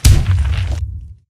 加入倭瓜碾压音效
squashing.ogg